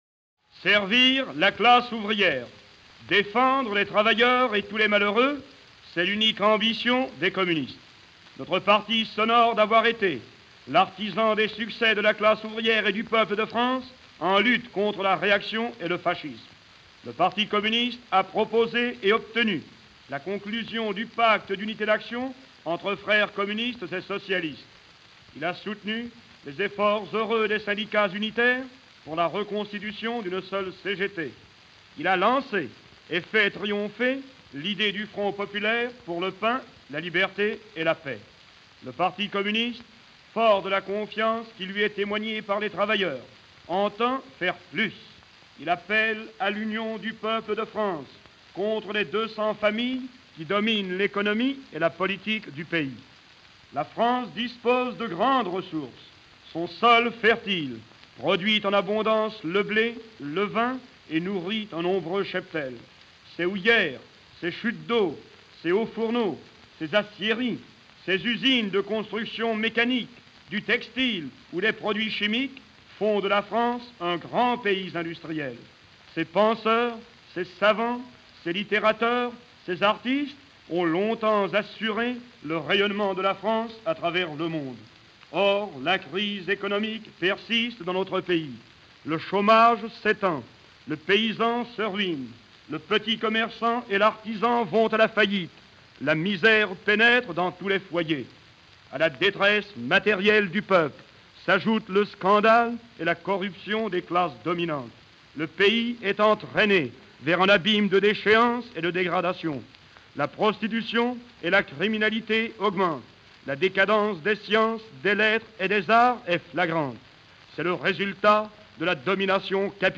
Discours de Maurice Thorez pour le Front Populaire